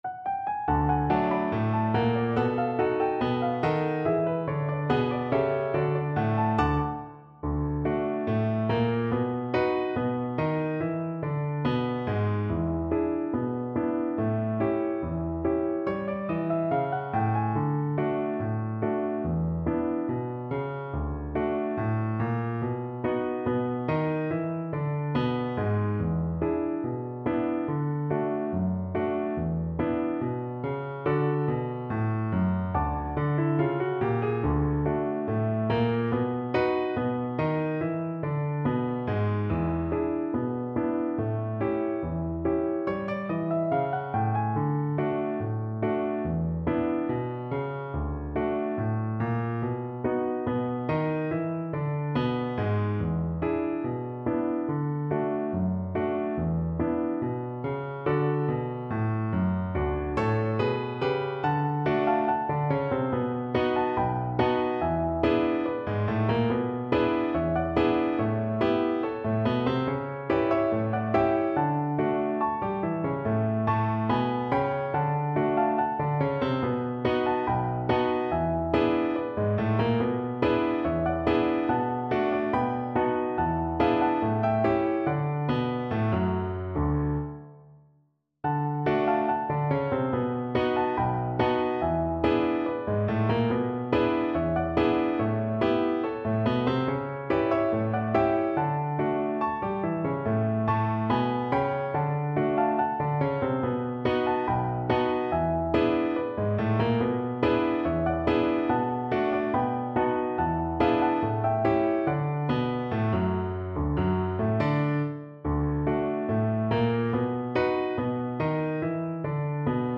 2/4 (View more 2/4 Music)
Slow Drag. = 90
A3-G5
Jazz (View more Jazz Cello Music)